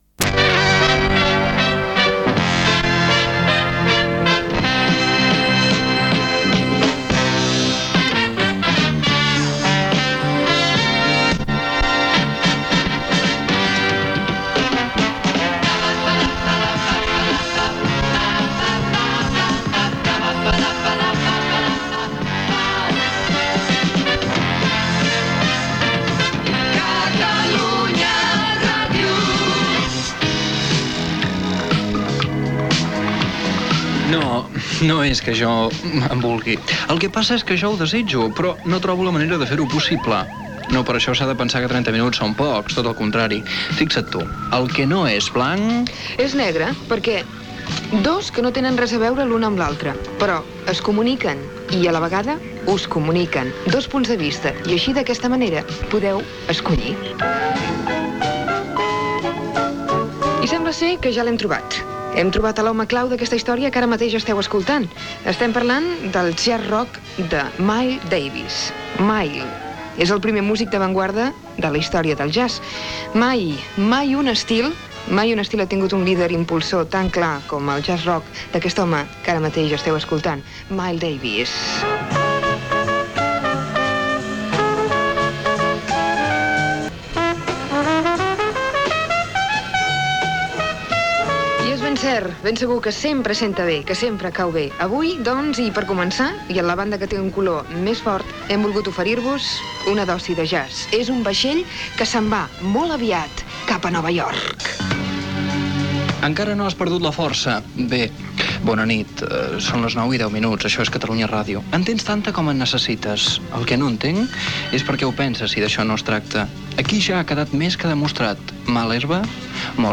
Sintonia de l'emissora, careta del programa, presentació, tema musical, hora, identificació de l'emissora i frase, tema musical de Simple Minds, repàs a ritmes musical negres i tema musical
Musical